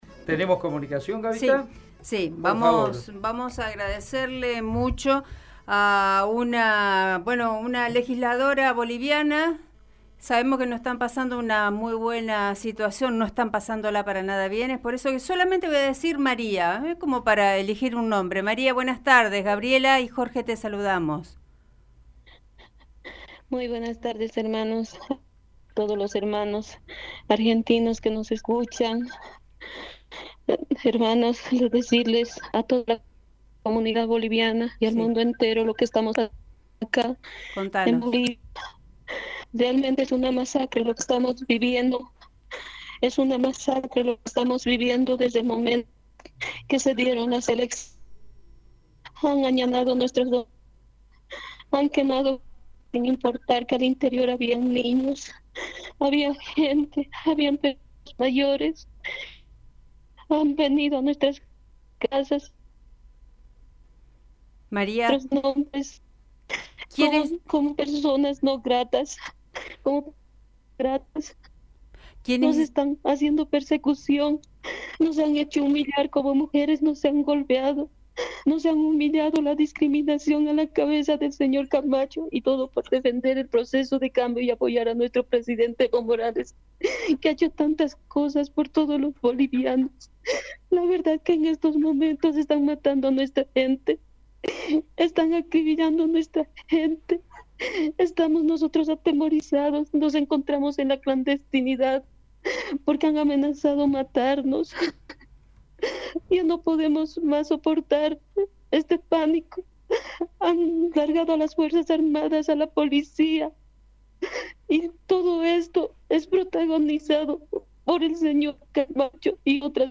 El llanto desgarrador de una Diputada de Bolivia en Radio EME | Radio EME
Una diputada Nacional, quien pidió reserva de nombre por miedo a la persecución, habló con Radio EME y se ahogó en llanto al describir el caos en que están sumidos. Desde el medio del monte, escondida y con poca señal, la legisladora pidió auxilio.
La mujer, con la respiración entrecortada por la angustia reveló que los han amenazado hasta con “violar a sus hijos”.